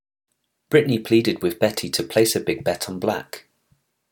B / P Tongue Twisters
The /b/ sound (voiced bilabial stop) and /p/ sound (voiceless bilabial stop) make up a voiced voiceless pair and are known as plosives.
The only difference is that you can hear the voice box engaged with the /b/ sound which makes it sound softer.